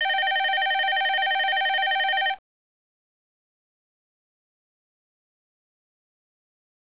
ring.wav